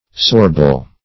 Search Result for " sorbile" : The Collaborative International Dictionary of English v.0.48: Sorbile \Sor"bile\, a. [L. sorbilis, fr. sorbere to suck in, to drink down.]